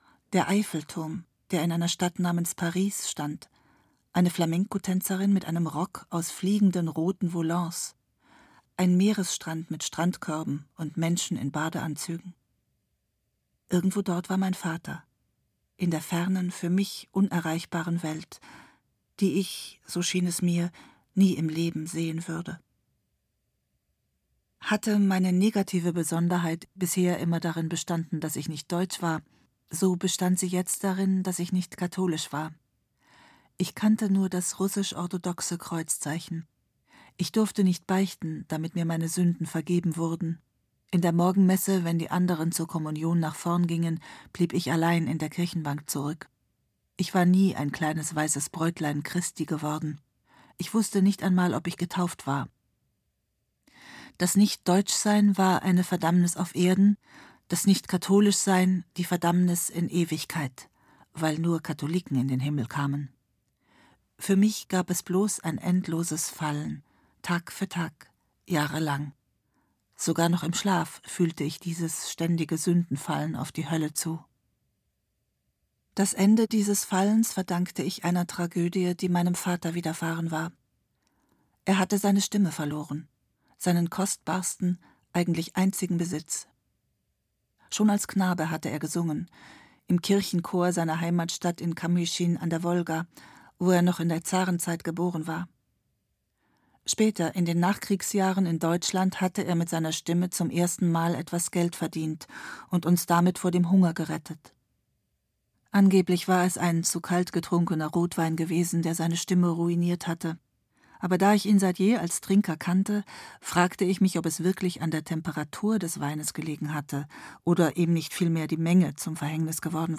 Irgendwo in diesem Dunkel Natascha Wodin (Autor) Martina Gedeck (Sprecher) Audio-CD 2018 | 1.